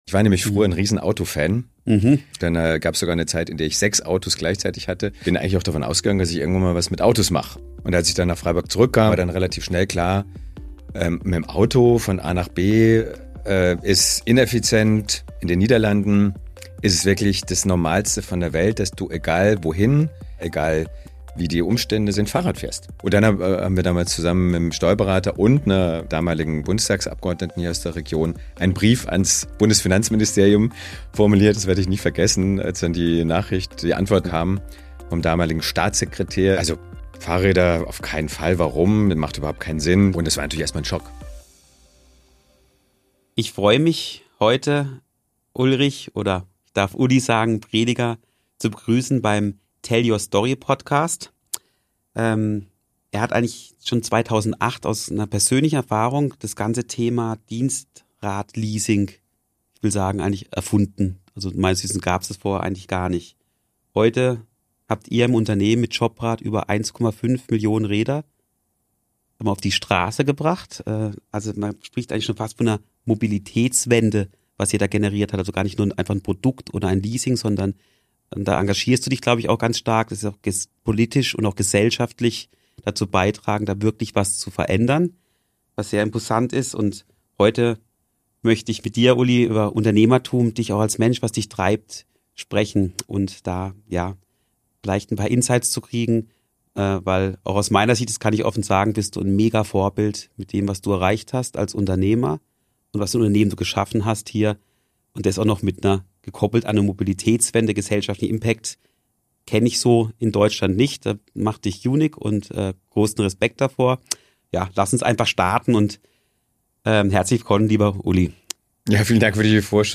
Ein inspirierender Talk über Unternehmertum, Mobilitätswende und die Zukunft nachhaltiger Mobilität.